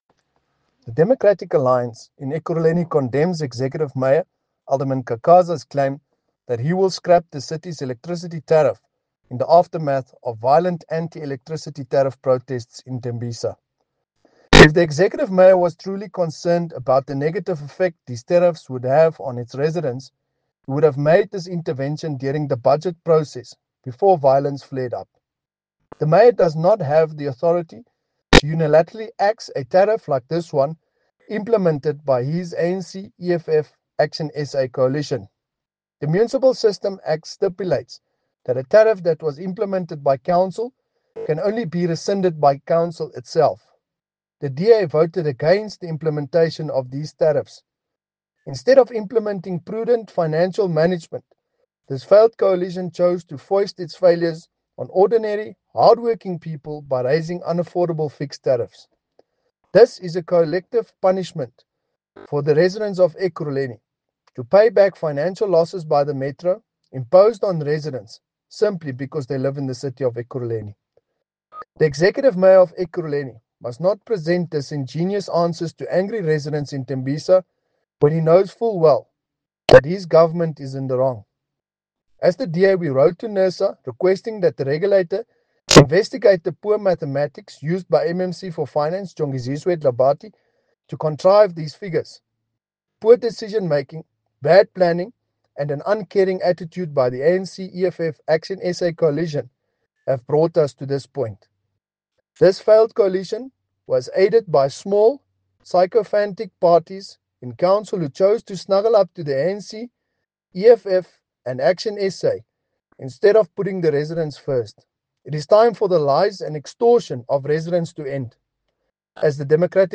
Note to Editors: Please find an English soundbite by Cllr Brandon Pretorius